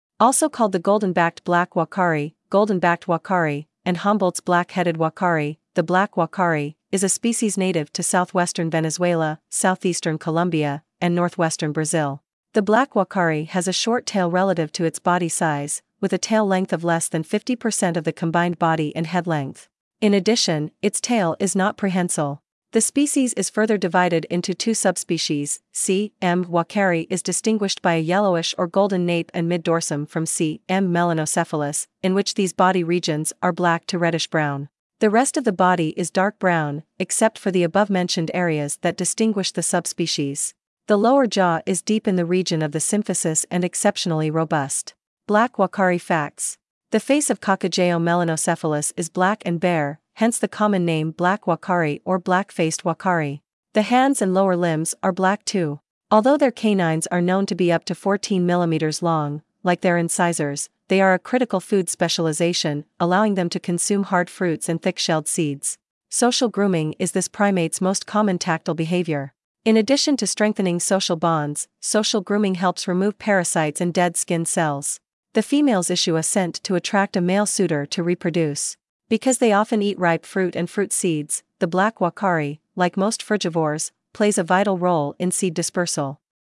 Black Uakari
black-uakari.mp3